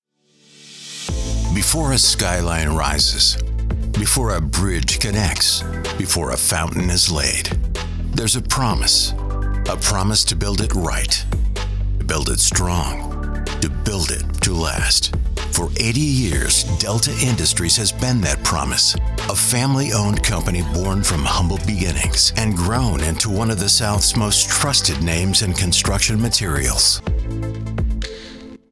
The Voice Realm represents versatile American and Canadian voice over talent with North American accents suited to international voice castings from small jobs to worldwide campaigns.
I have a real, relatable, trustworthy mid-range voice.